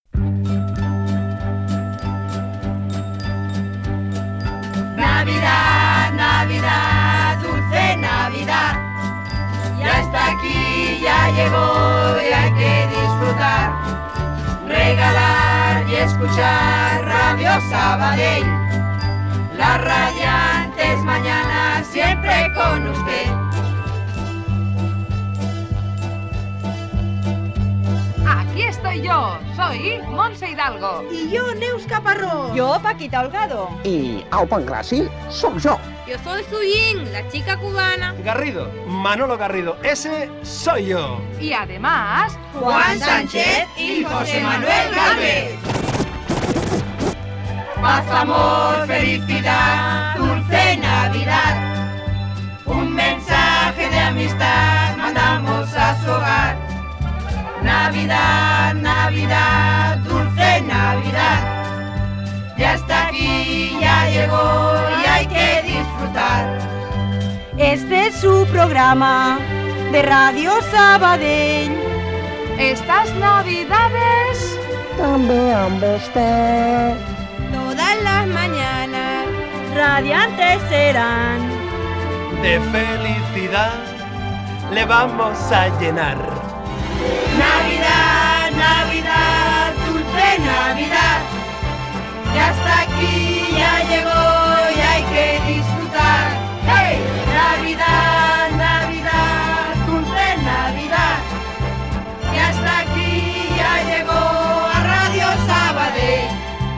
Nadala cantada del programa
Entreteniment